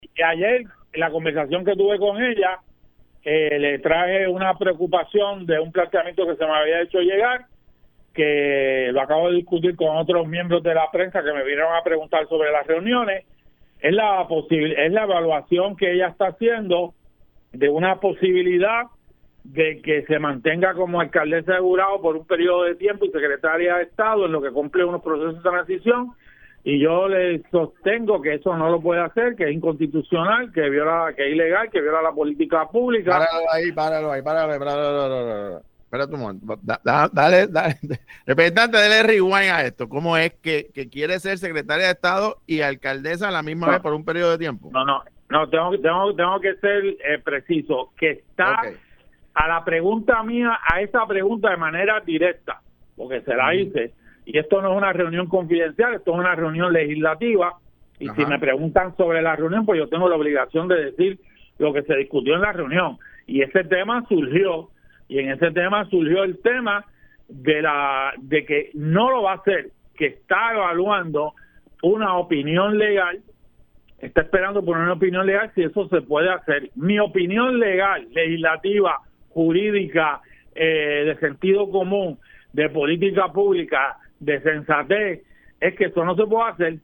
Así lo reveló el representante Denis Márquez en el programa Los Colberg